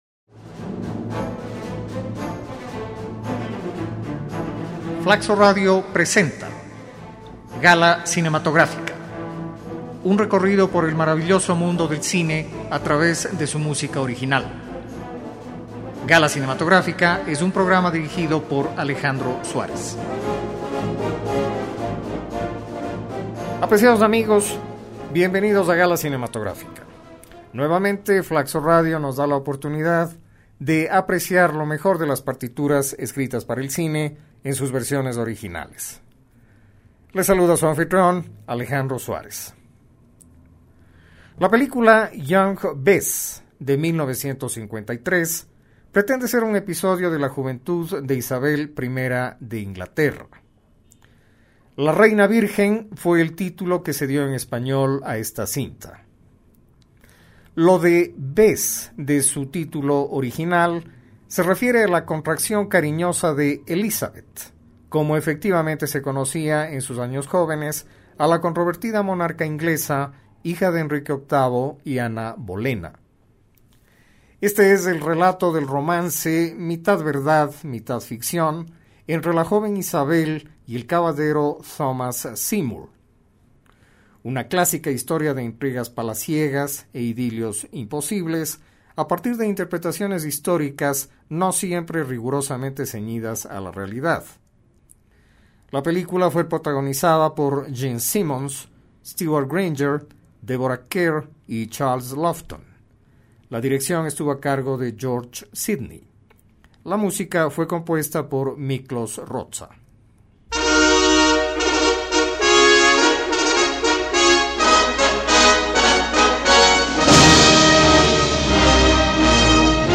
temas originales de la música renacentista inglesa
pieza de baile